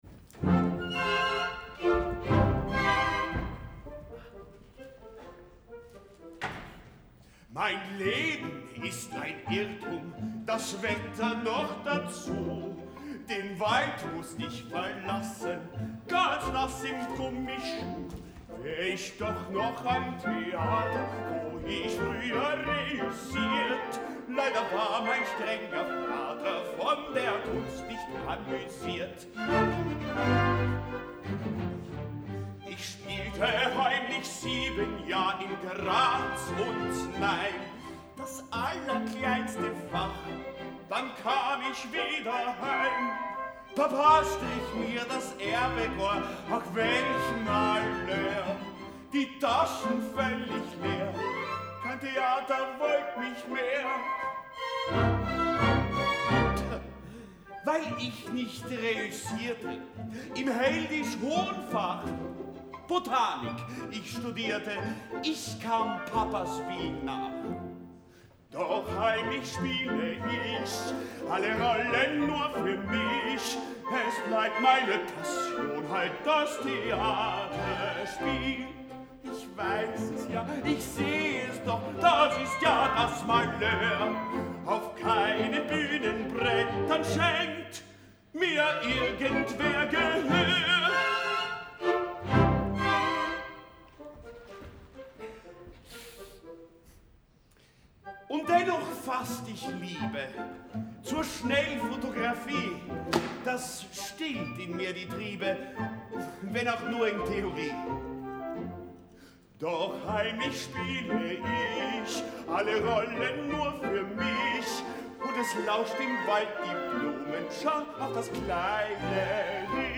Auftrittscouplet Erasmus Friedrich Müller
Orchester des Staatstheaters am Gärtnerplatz